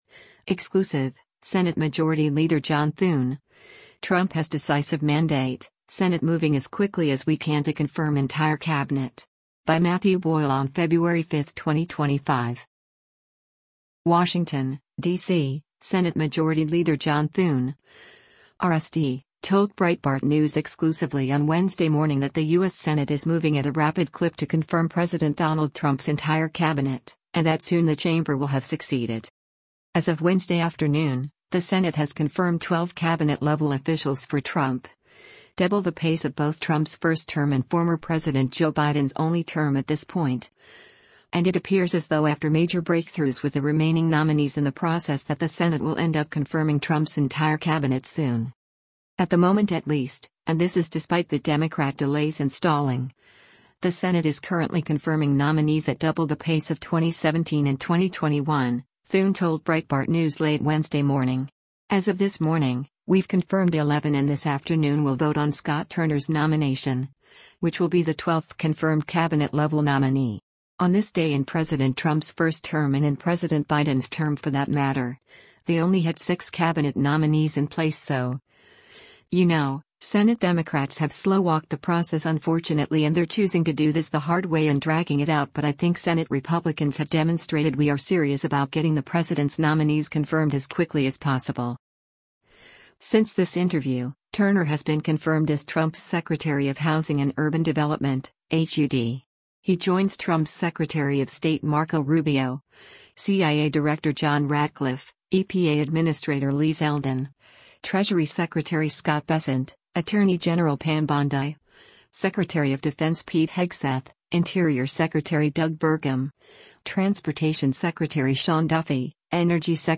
WASHINGTON, DC — Senate Majority Leader John Thune (R-SD) told Breitbart News exclusively on Wednesday morning that the U.S. Senate is moving at a rapid clip to confirm President Donald Trump’s entire Cabinet, and that soon the chamber will have succeeded.